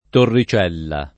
torri©$lla] s. f. — sim. i top. Torricella (es.: Torricella del Pizzo [torri©$lla del p&ZZo], Lomb.; Torricella Sicura [torri©$lla Sik2ra], Abr.; ecc.), Torricelle (E.-R.), e i cogn. Torricella, Torricelli